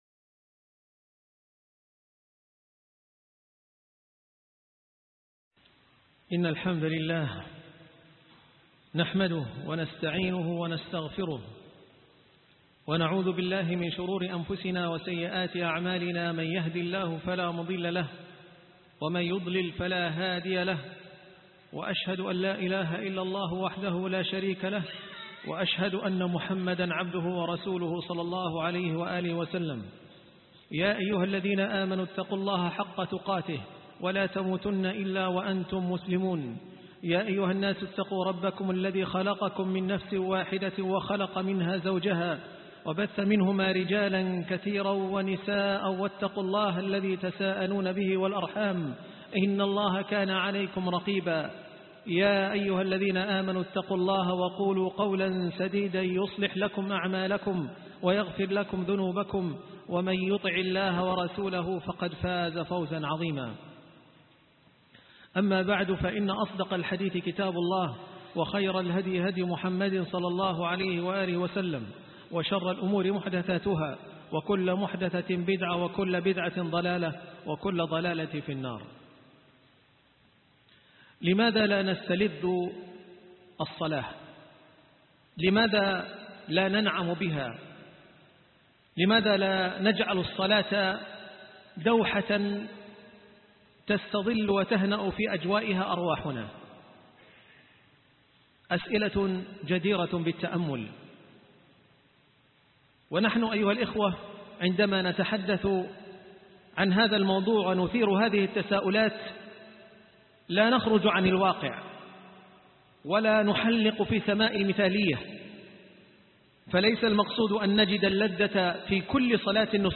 الصلاة نعيم الروح 2 _ خطبة الجمعة